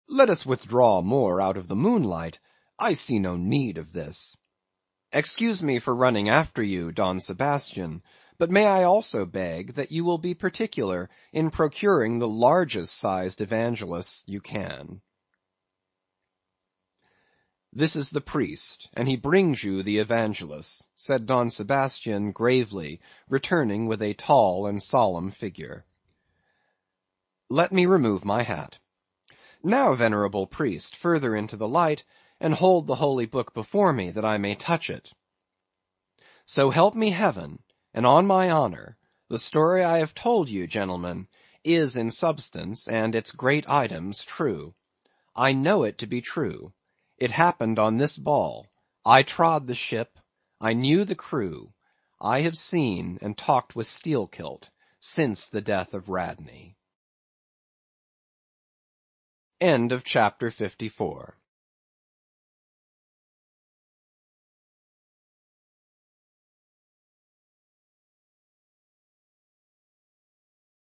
英语听书《白鲸记》第561期 听力文件下载—在线英语听力室